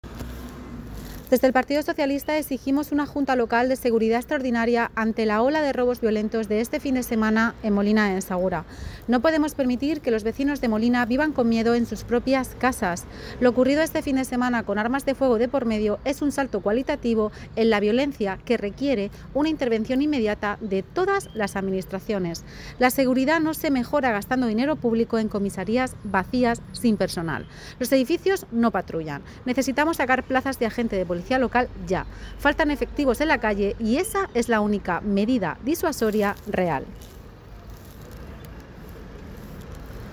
Isabel Gadea, portavoz del PSOE en Molina de Segura, ha sido tajante: